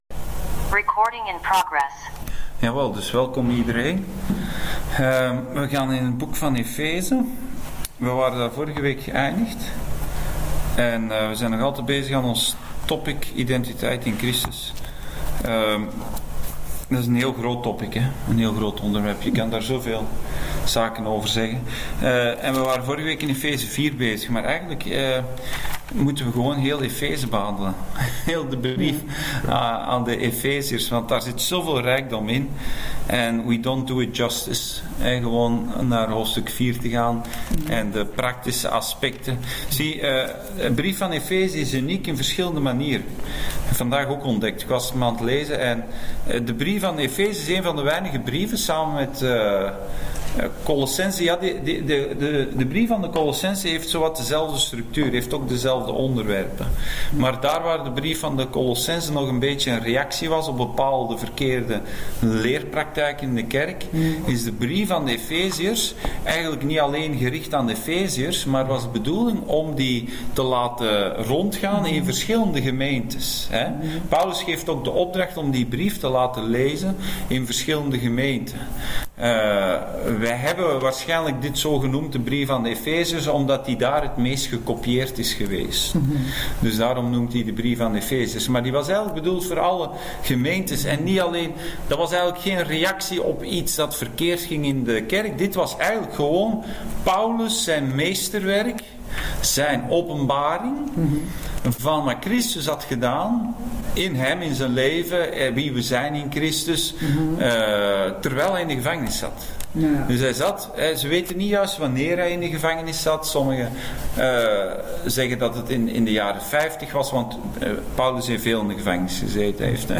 Bijbelstudie: brief naar Efeze